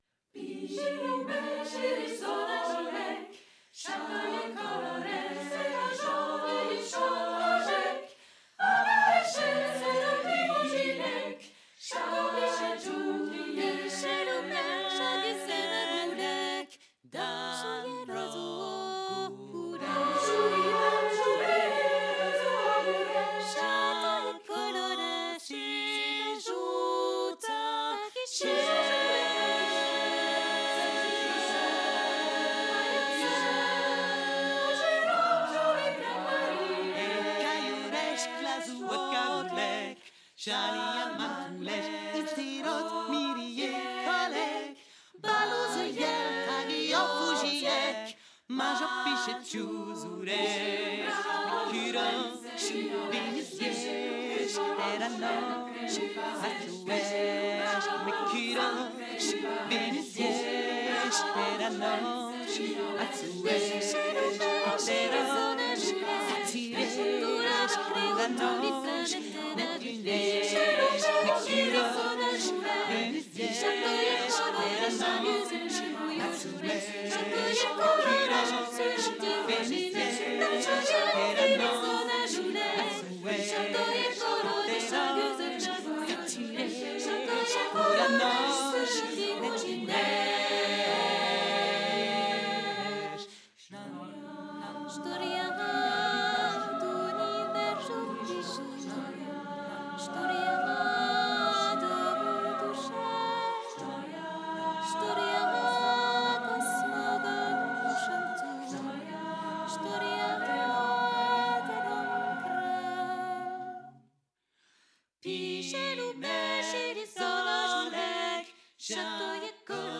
30 voix de femmes et une guitare
Guitare